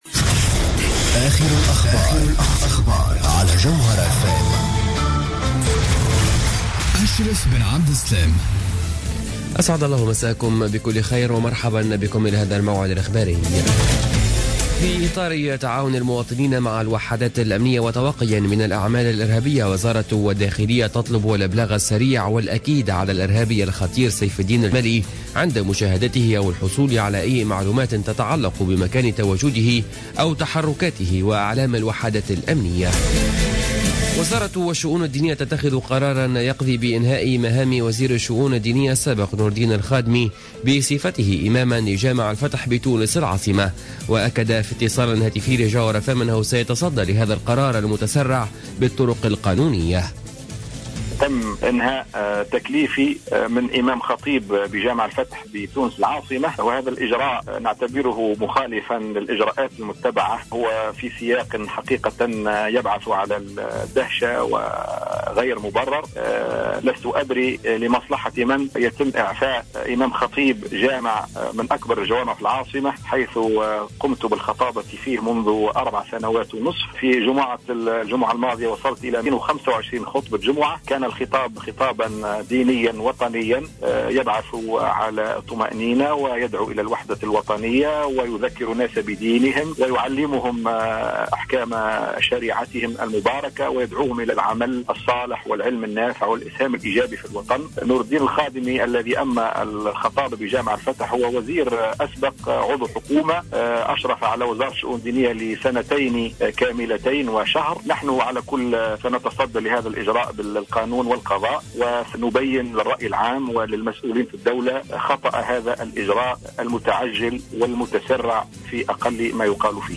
نشرة أخبار السابعة مساء ليوم السبت 08 أوت 2015